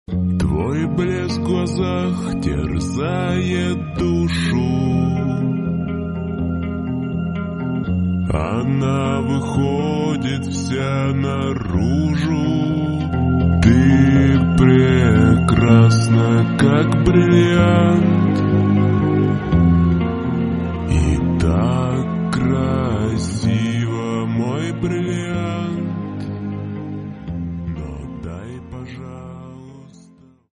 мужской голос
крутые
цикличные